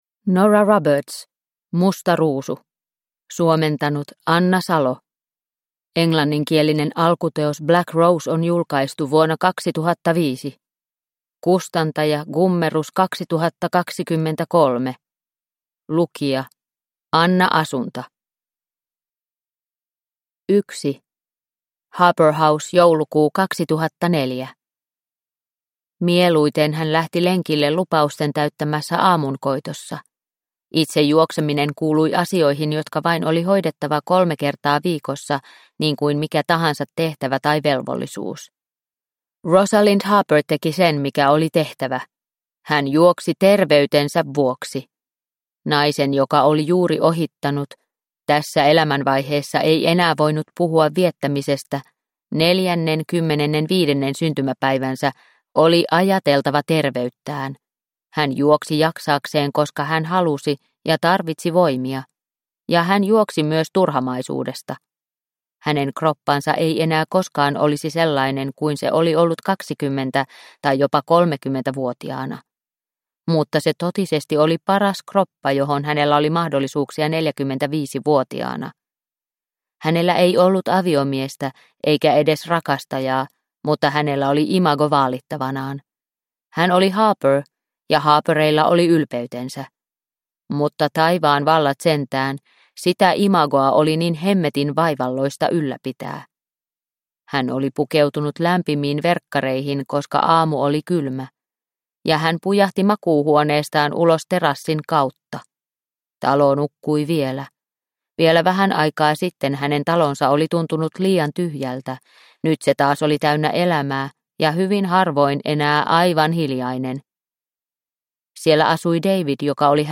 Musta ruusu – Ljudbok – Laddas ner